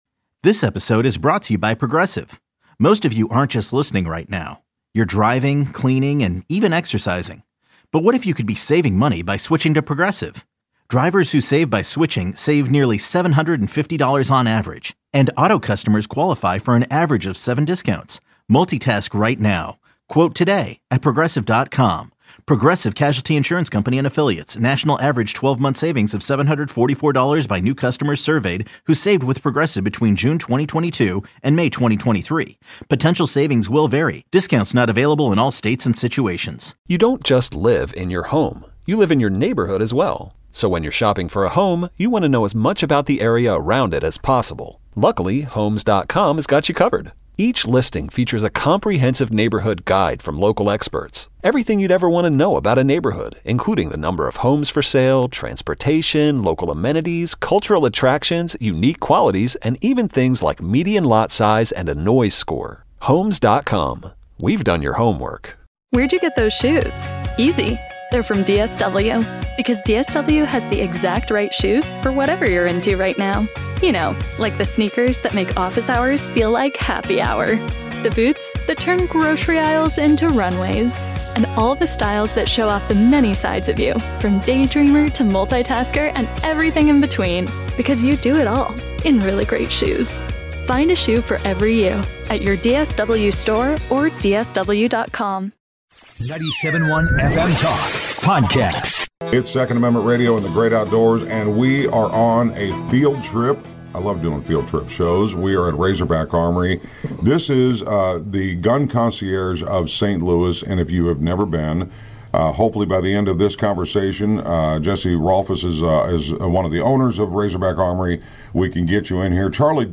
But, as a very special added bonus, we also got to sit down with Pogues legend Spider Stacy at his very own kitchen table which, unsurprisingly, was one of our favourite episodes of the year.